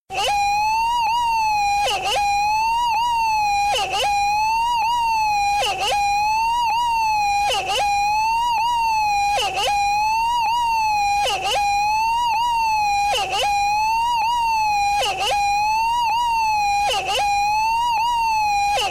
Play, download and share VarSIRENus original sound button!!!!
siren.mp3